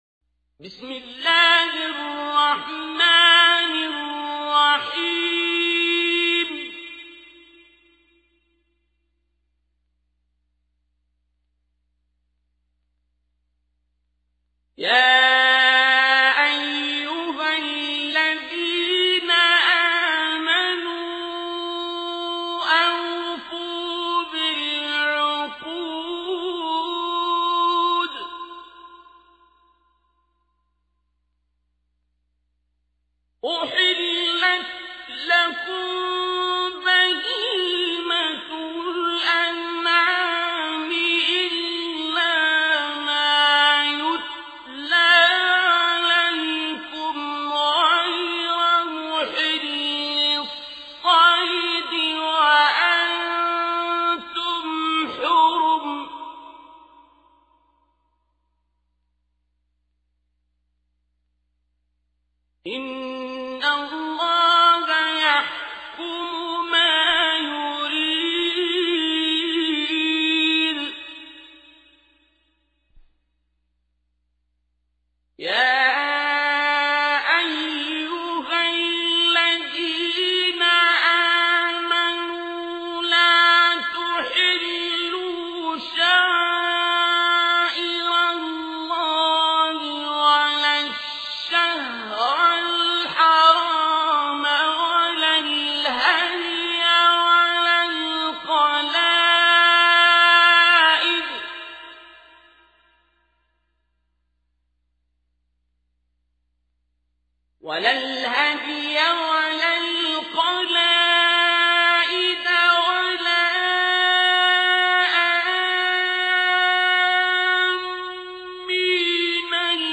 تحميل : 5. سورة المائدة / القارئ عبد الباسط عبد الصمد / القرآن الكريم / موقع يا حسين